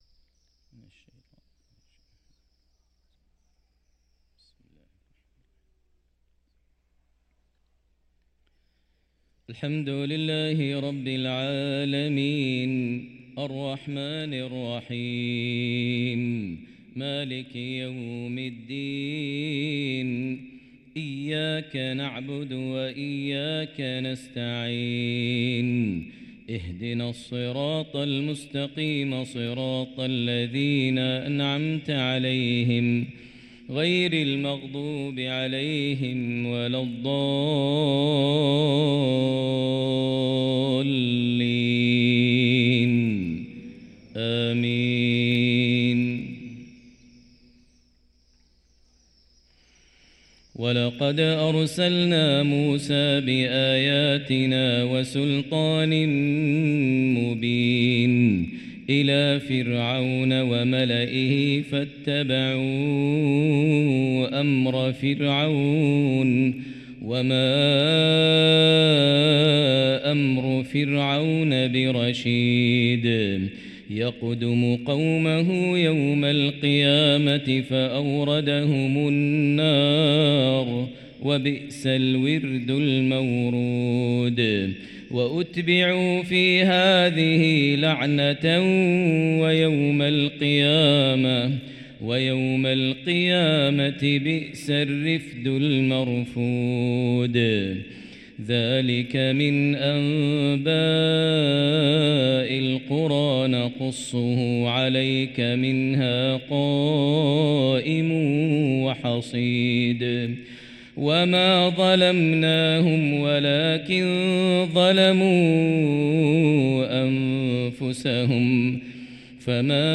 صلاة الفجر للقارئ ماهر المعيقلي 18 رجب 1445 هـ
تِلَاوَات الْحَرَمَيْن .